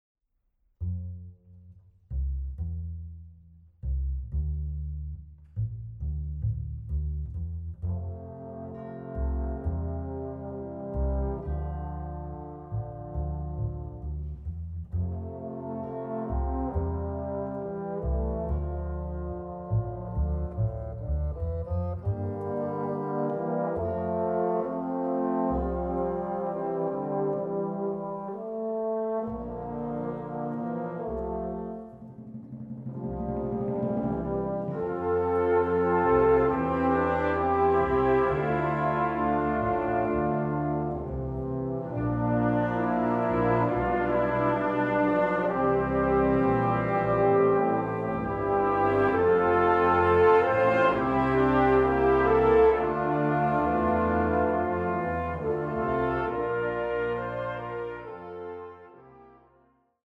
Music for Symphonic Wind Orchestra